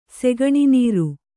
♪ segaṇi nīru